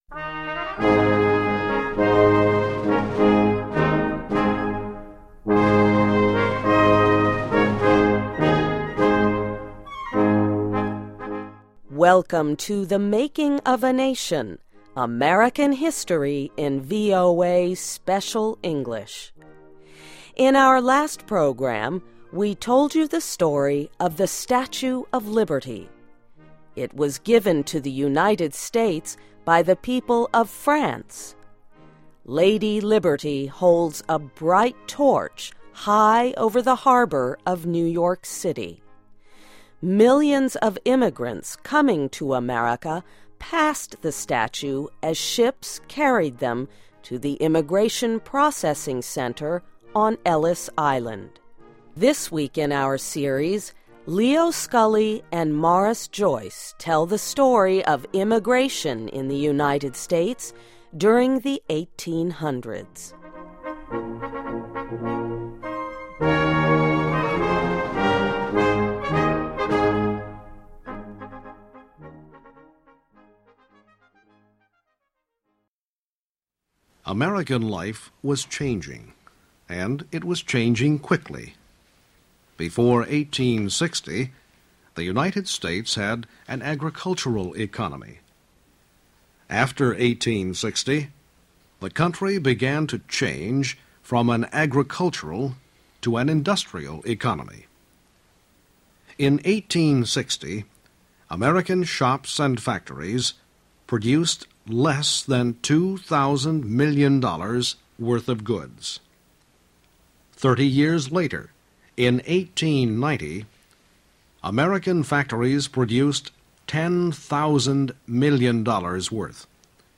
Click Arrow to Hear This Program: Play Audio File Or download MP3 (Right-click or option-click and save link) Welcome to THE MAKING OF A NATION -- American history in VOA Special English.